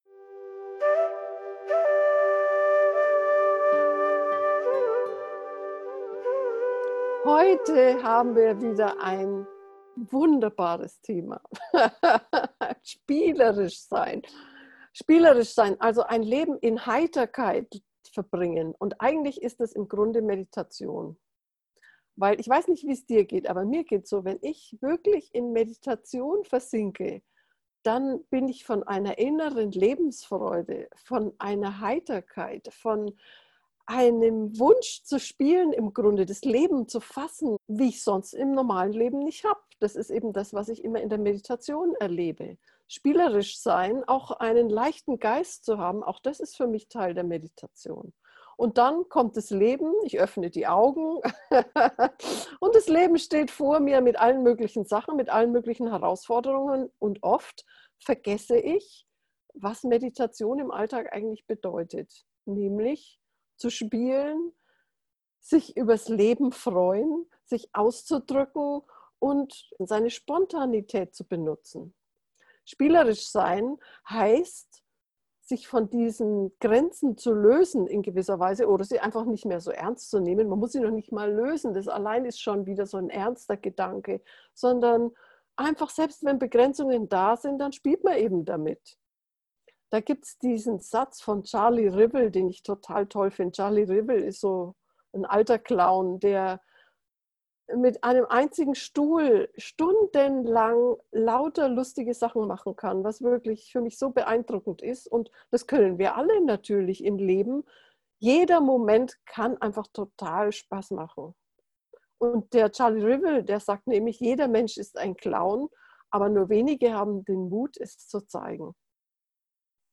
Geführte Meditationen